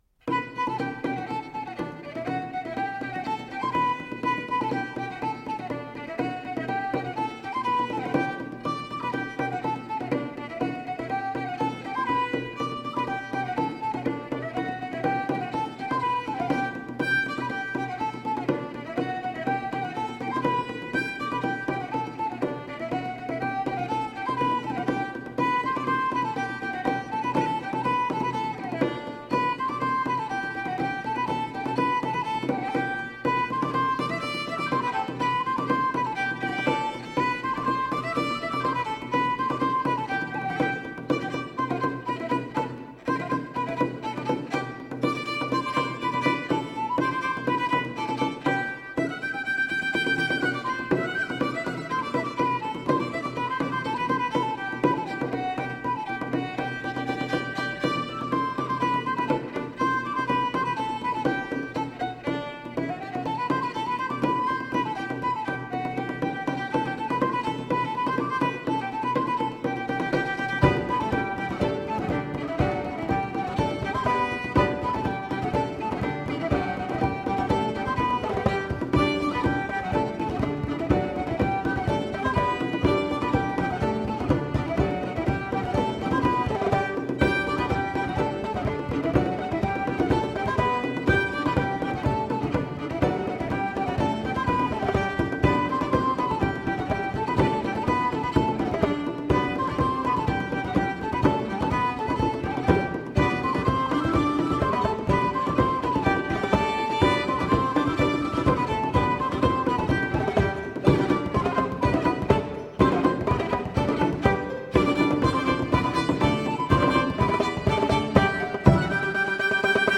Medieval Dance Music.